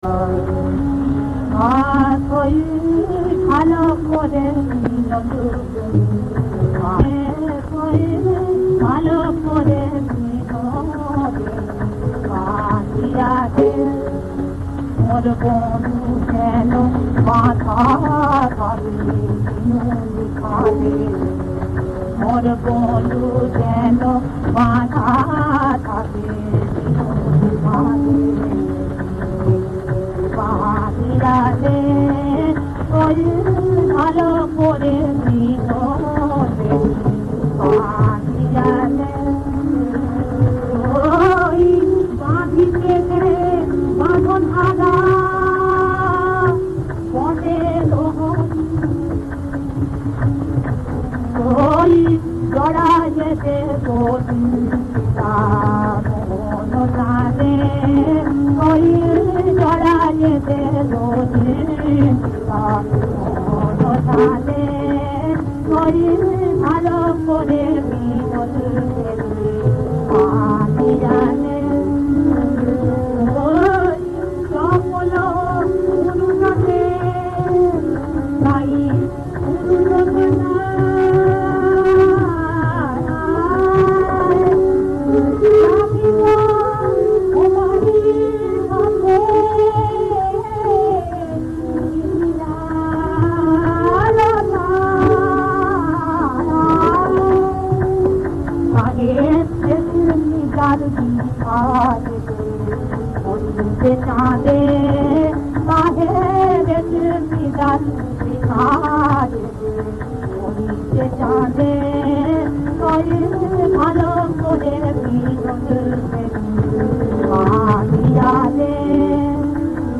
• রাগ: সিন্ধু-ভৈরবী
• তাল: কাহারবা